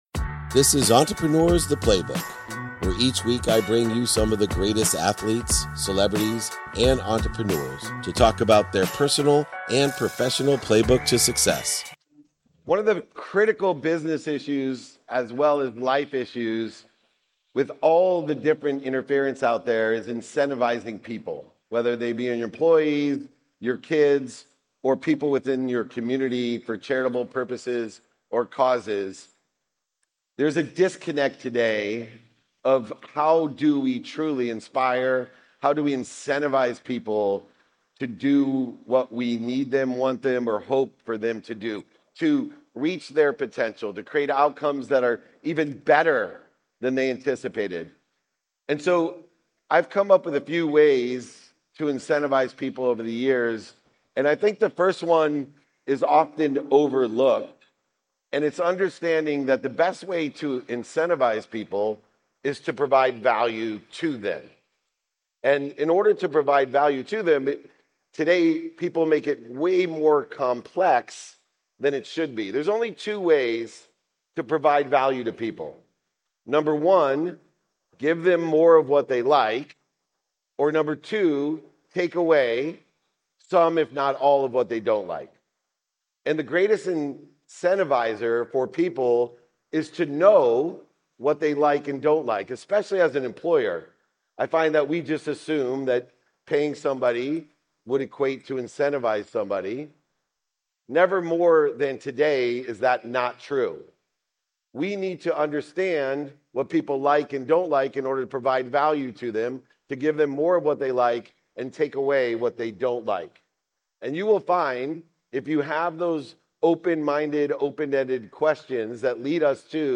Today’s episode is from a keynote speech at Web Summit in which I explore five effective strategies to incentivize and inspire your team. First, we talk about providing value - understanding and catering to what your team members like and removing what they don't. Next, I emphasize the importance of encouraging team members to seek help, fostering a supportive environment. We then delve into the significance of time management, highlighting how it enhances productivity, accessibility, and gratitude.